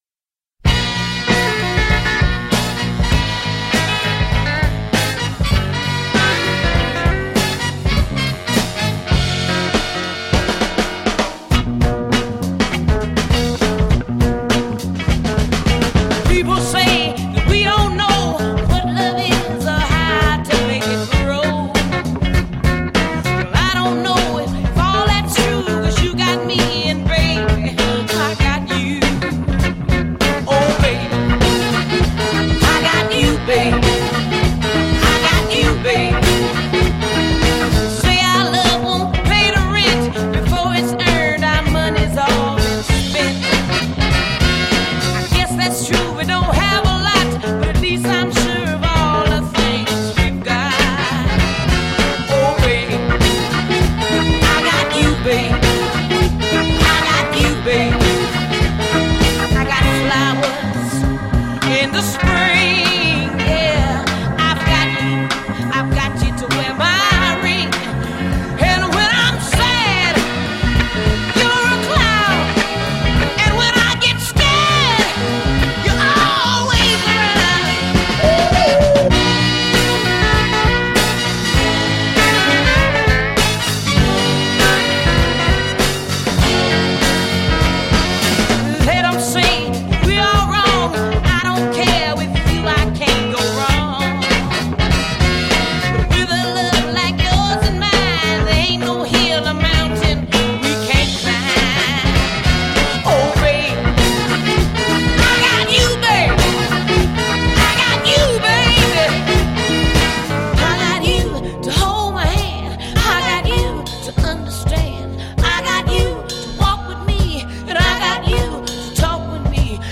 Wish the fidelity was better here… but maybe not.